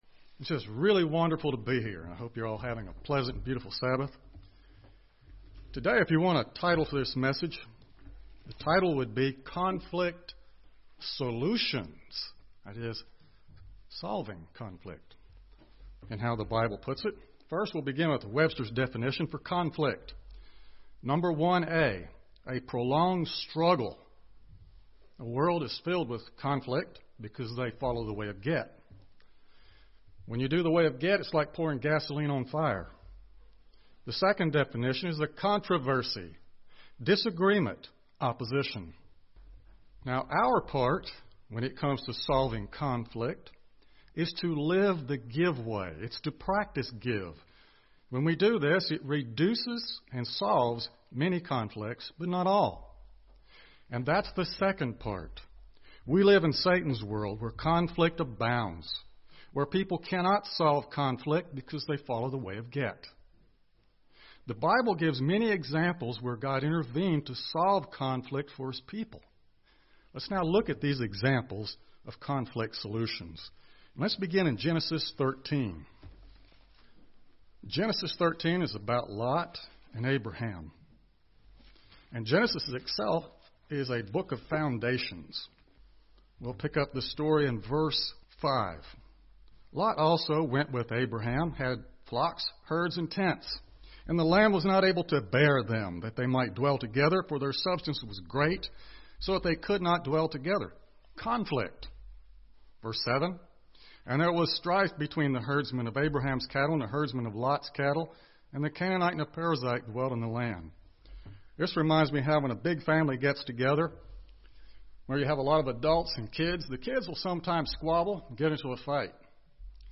Through examples in the book of Genesis, God reveals solutions to conflicts that arise in human relationships. This sermon describes how conflicts in the lives of Abraham, Isaac, Jacob and others were solved. Prayer, humility and the way of give soothe over the friction that often arises between people.